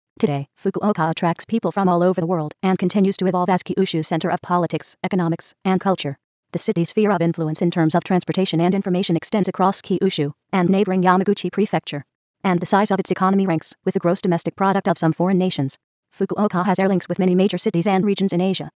注：上表の音声データはTextAloudによるコンピュータ合成音です。